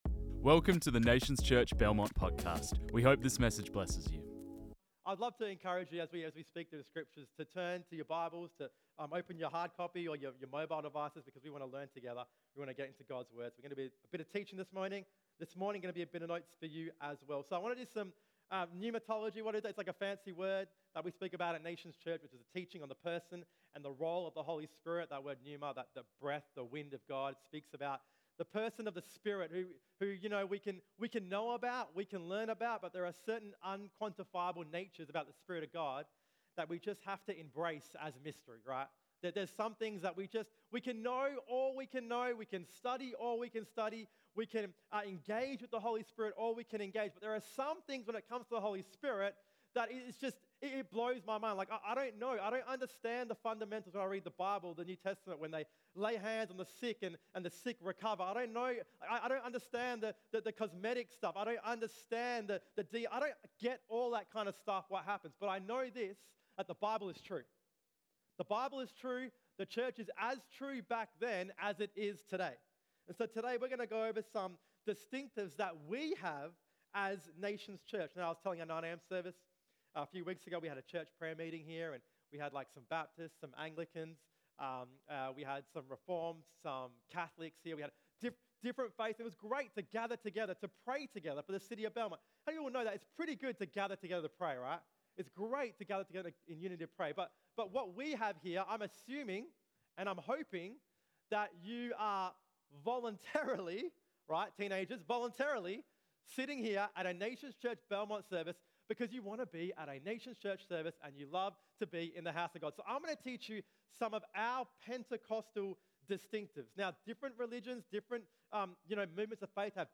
This message was preached on 28 May 2023.